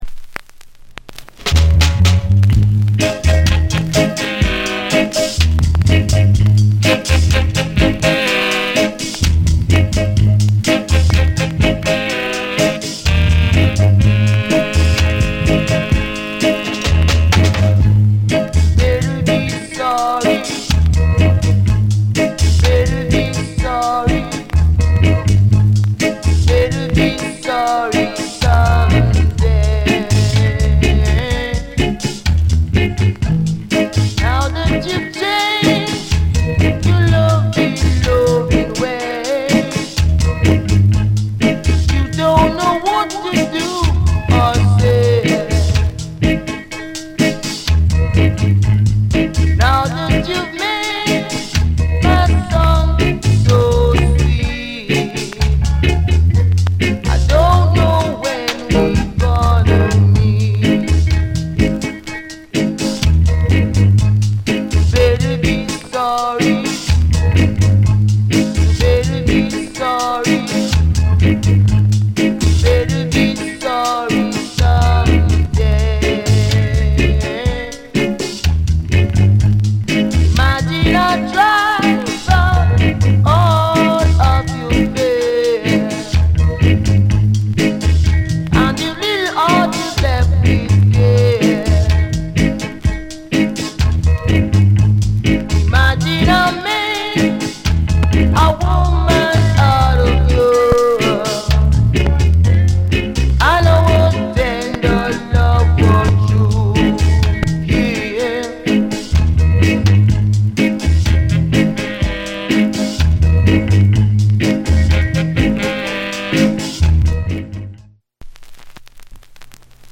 * ほぼほぼ綺麗のですが半ばに小さな傷二つありぱちノイズ３回でます。